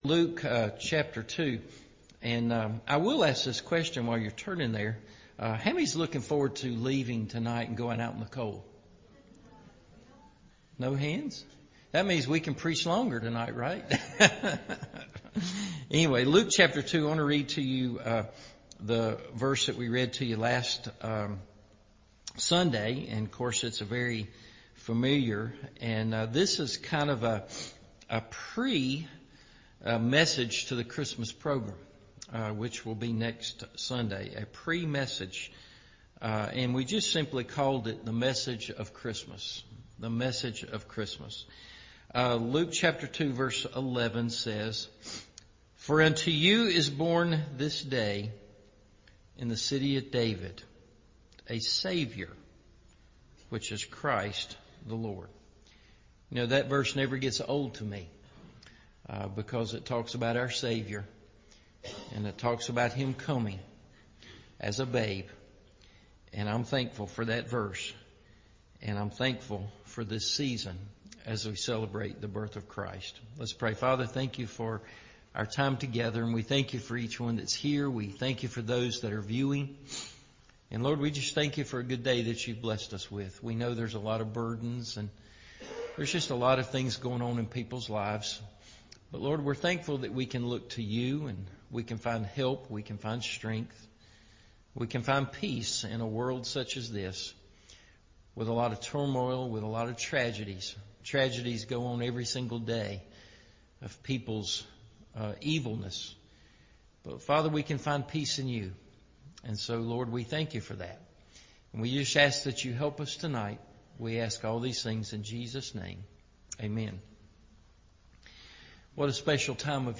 The Message of Christmas – Evening Service